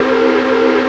STEAM 77.wav